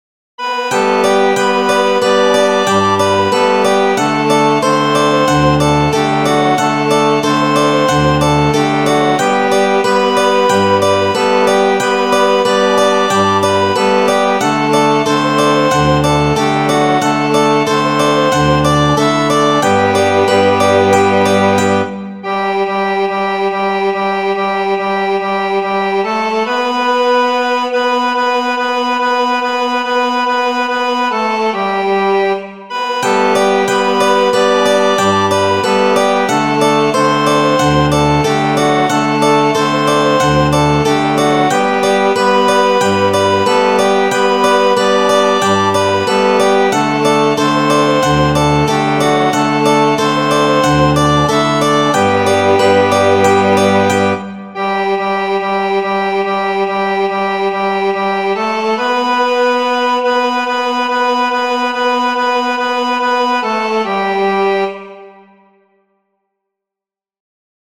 – Contos tradicionais musicados e dramatizados.